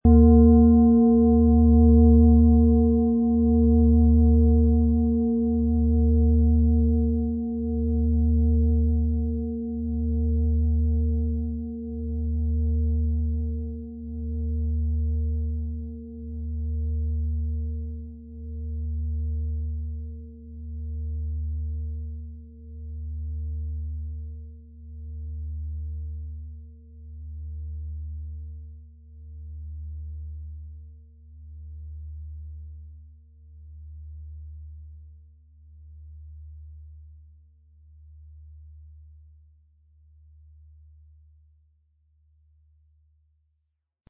XXL-Planeten-Fußklangschale - Emotionen fühlen und geborgen sein
• Sanfte Schwingung: Berührend und ausgleichend – ideal für emotionale Tiefe.
Unter dem Artikel-Bild finden Sie den Original-Klang dieser Schale im Audio-Player - Jetzt reinhören.
MaterialBronze